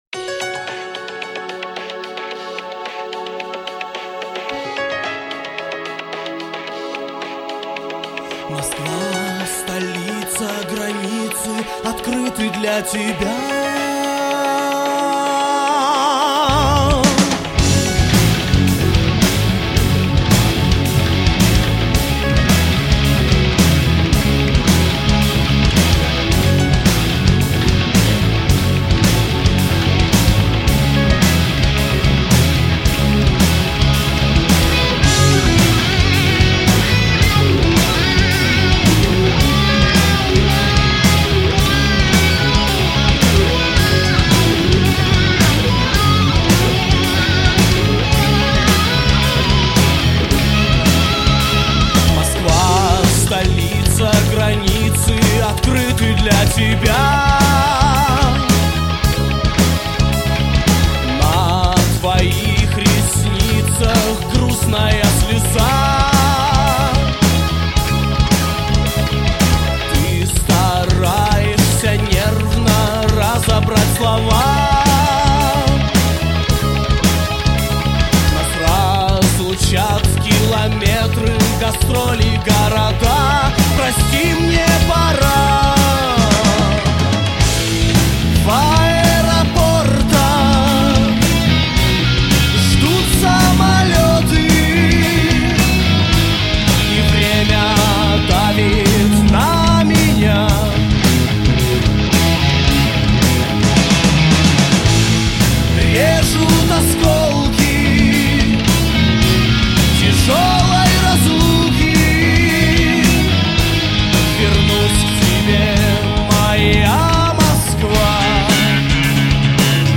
играющей нестандартную альтернативную музыку.